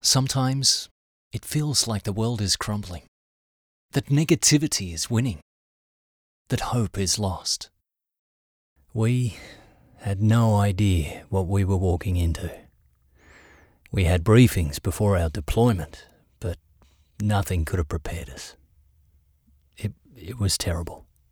Award-winning Australian Voice. versatile, clear, and seriously experienced.
• Soft Sell
• Professional Voice booth – acoustically treated.